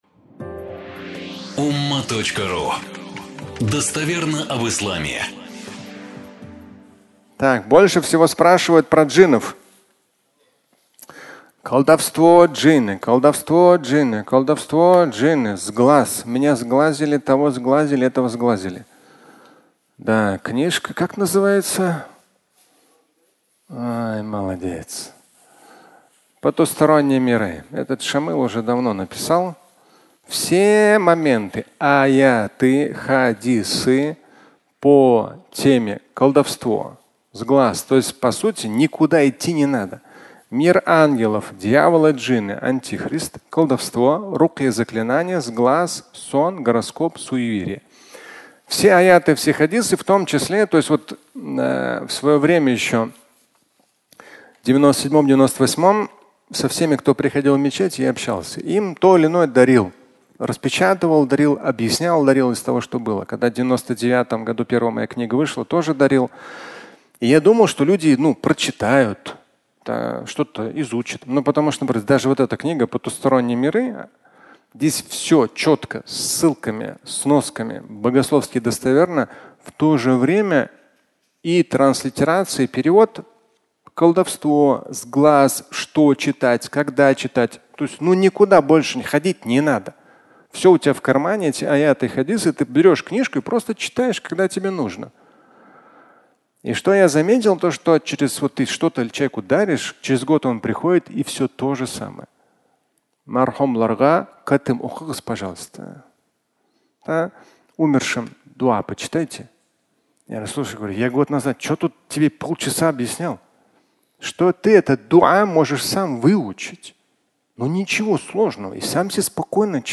Колдовство и джинны (аудиолекция)
Фрагмент пятничной лекции